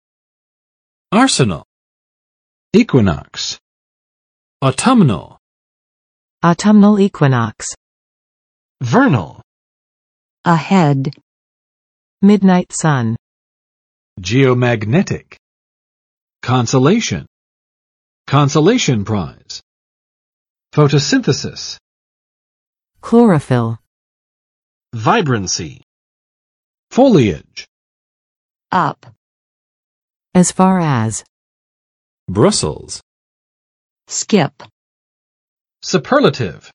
[ˋɑrsnəl] n. 军械库；兵工厂；储藏的武器